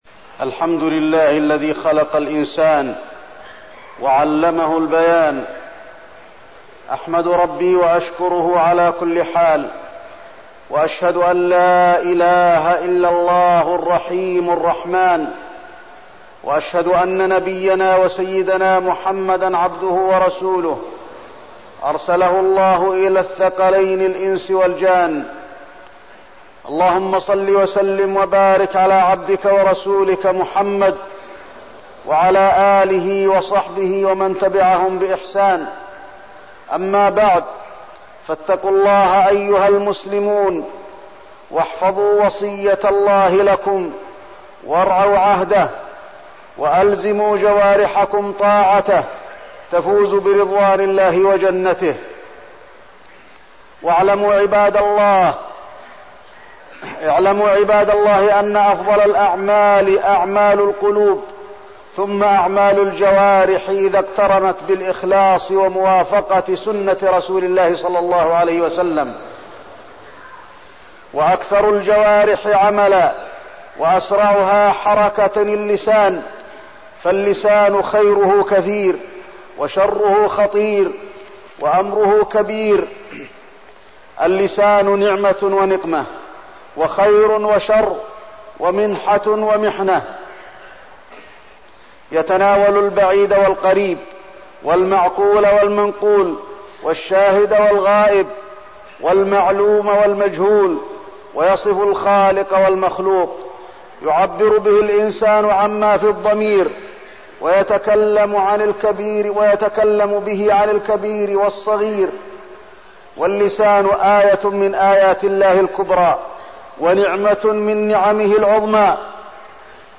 تاريخ النشر ٢٠ جمادى الآخرة ١٤١٤ هـ المكان: المسجد النبوي الشيخ: فضيلة الشيخ د. علي بن عبدالرحمن الحذيفي فضيلة الشيخ د. علي بن عبدالرحمن الحذيفي أضرار اللسان